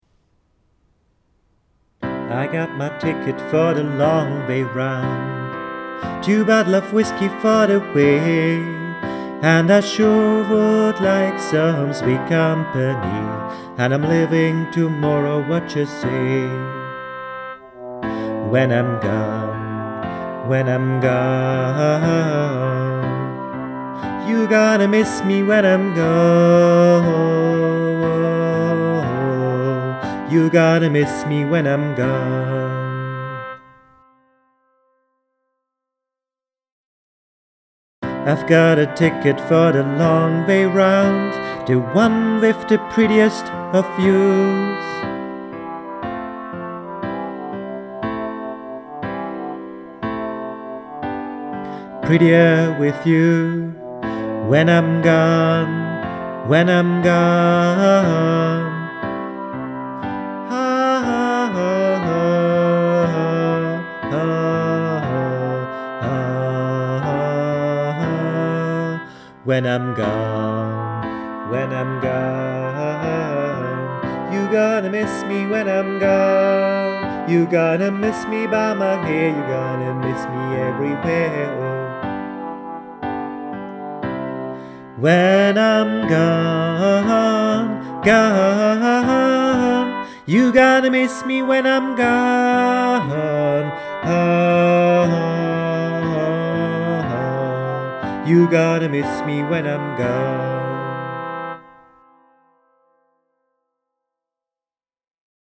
1. Stimme gesungen (mit Begleitung)
2. Stimme gesungen (mit Begleitung)